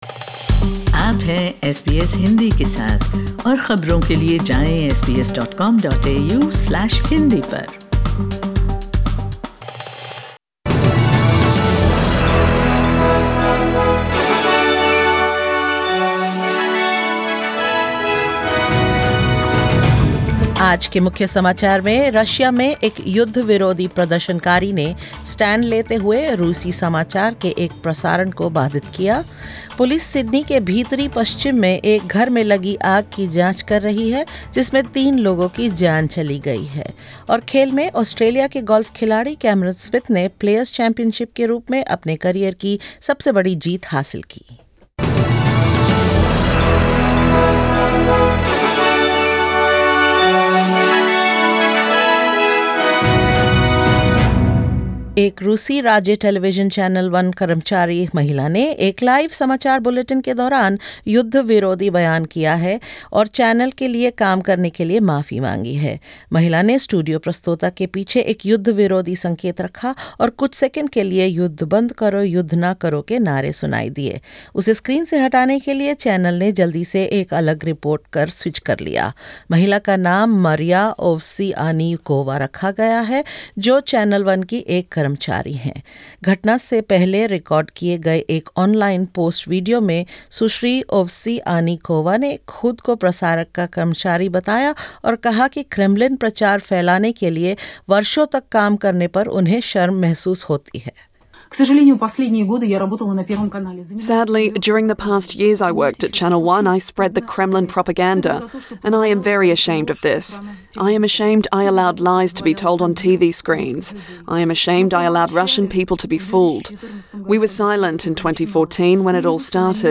In this latest SBS Hindi bulletin: An anti-war protester disrupts a live news bulletin on Russian TV; Police investigate a suspicious house fire in Sydney that has claimed three lives; Australian Golfer Cameron Smith takes out the Players Championship and more